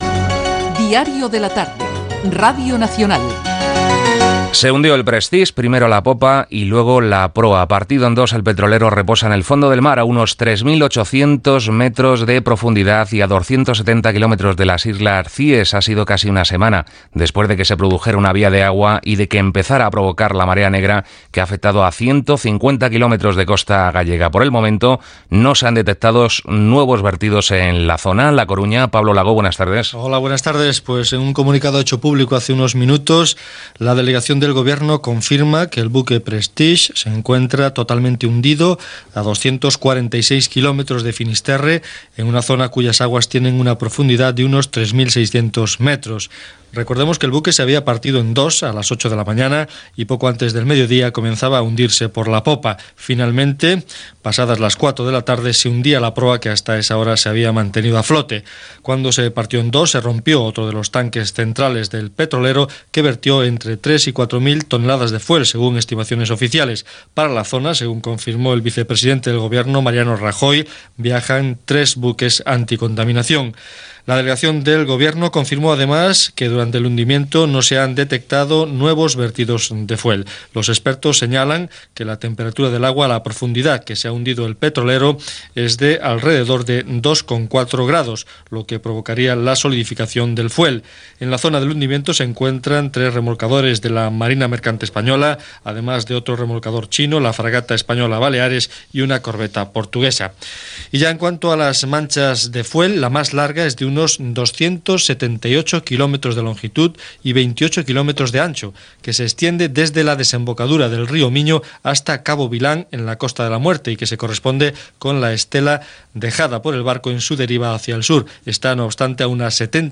(declaracions del president Mariano Tajoy i de Loyola de Palacio) Gènere radiofònic Informatiu